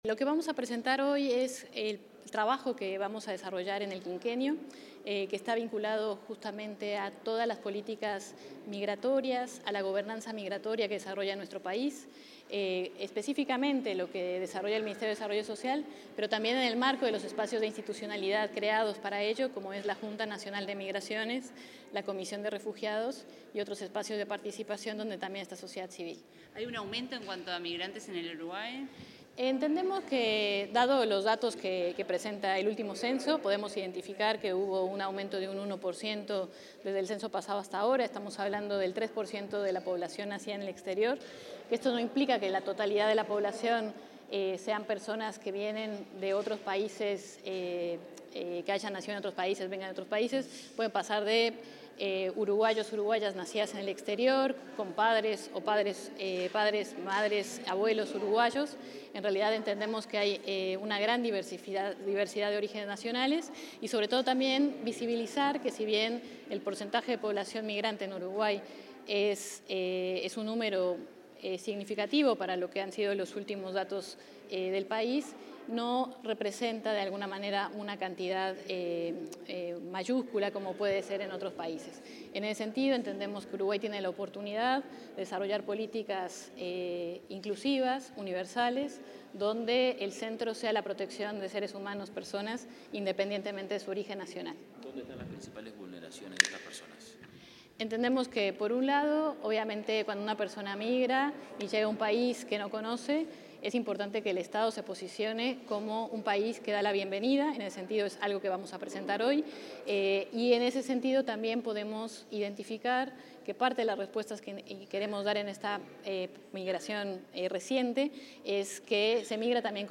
Declaraciones de la directora de Protección Social para Personas Migrantes, Valeria España 18/11/2025 Compartir Facebook X Copiar enlace WhatsApp LinkedIn En la presentación de las líneas de acción y los compromisos 2026-2030 en materia de protección social para personas migrantes, la directora del área, Valeria España, realizó declaraciones a la prensa.